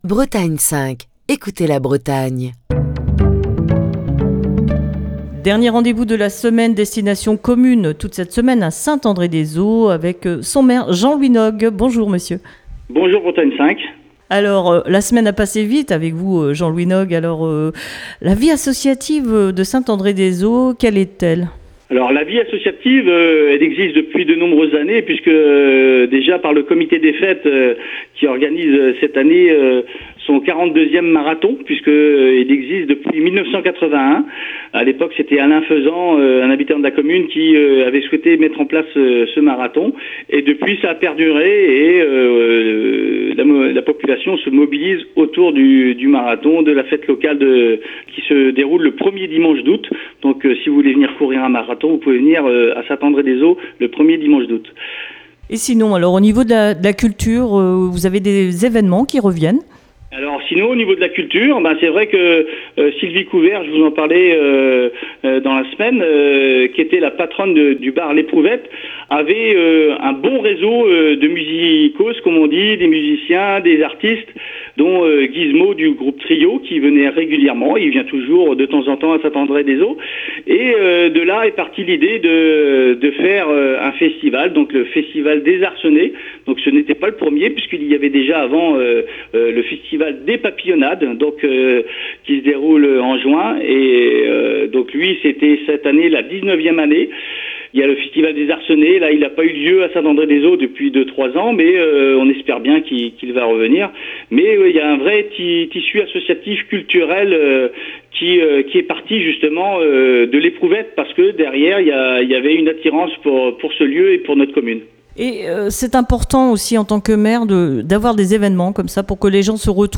Jean-Louis Nogues, maire de Saint-André-des-Eaux, est au micro